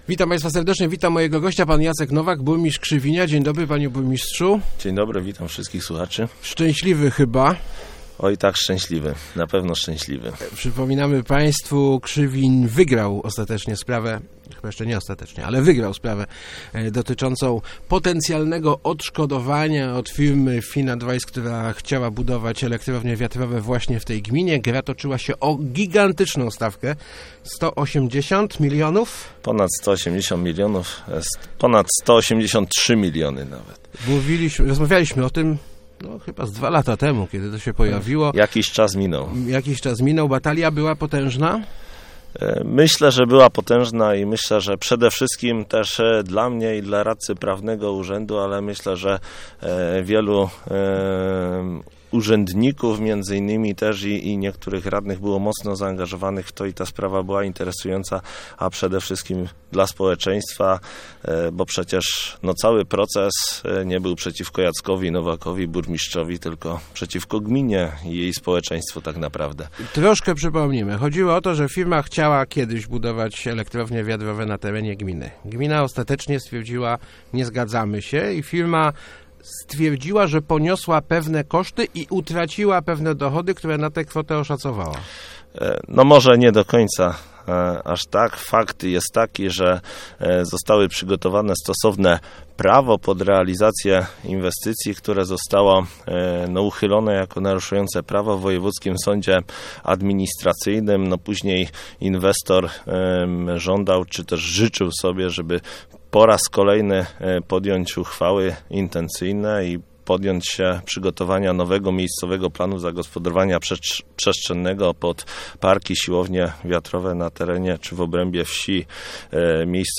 jnowak80.jpgByłem przekonany, że mamy rację, choć tuż przed wyrokiem była niepewność - mówił w Rozmowach Elki burmistrz Krzywinia Jacek Nowak. Sąd oddalił pozew firmy Finadvice, która domagała się ponad 180 milionów złotych od gminy tytułem utraconych zysków z powodu odmowy zgody na budowę siłowni wiatrowych.